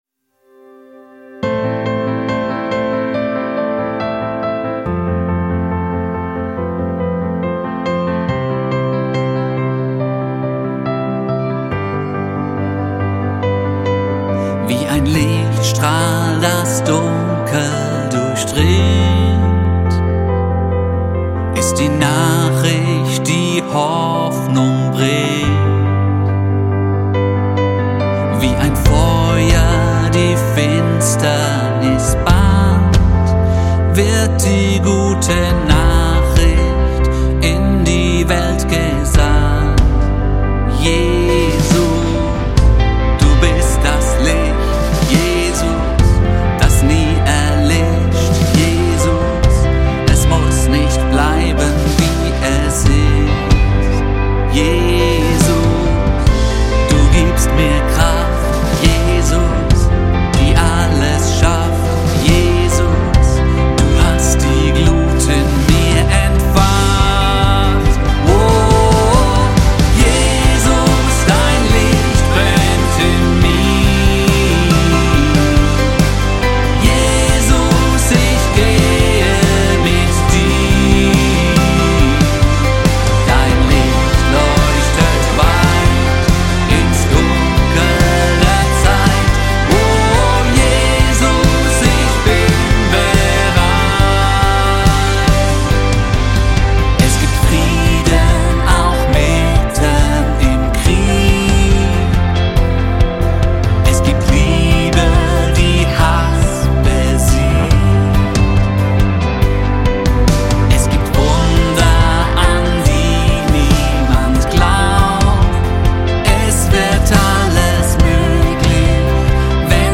Unser neues Lied für die Missionsfeste.
Schlagzeug
Bass
Gitarren
Piano, Programming
Add. Keyboards
Gesang